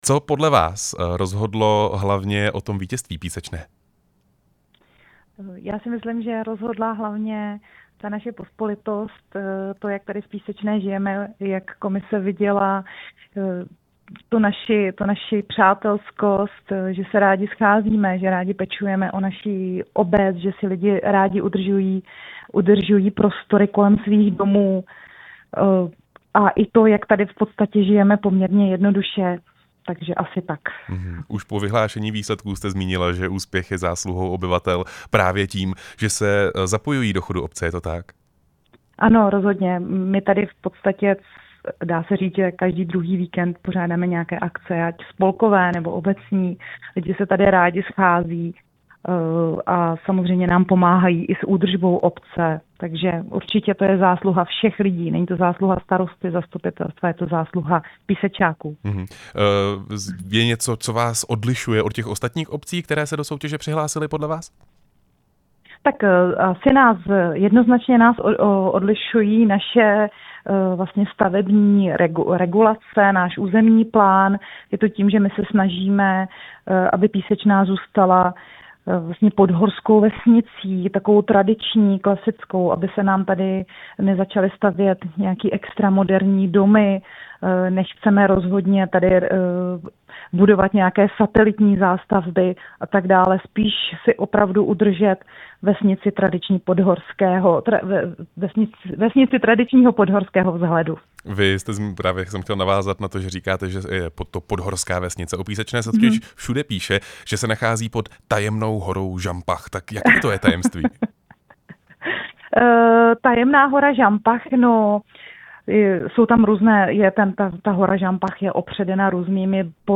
Rozhovor se starostkou Písečné Šárkou Šimkovou Filipovou